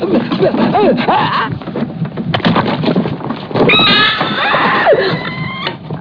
Kane suddenly screams out and blood explodes from his chest.
Kane starts convulsing, yelling and throwing his arms about.